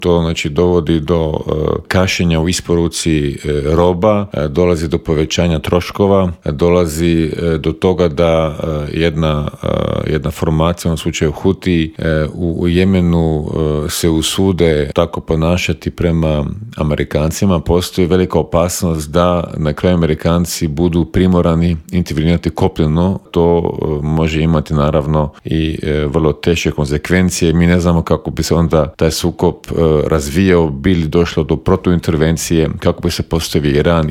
Bivšeg ministra vanjskih i europskih poslova Miru Kovača u Intervjuu Media servisa upitali smo - je li ga iznenadio postupak SAD-a?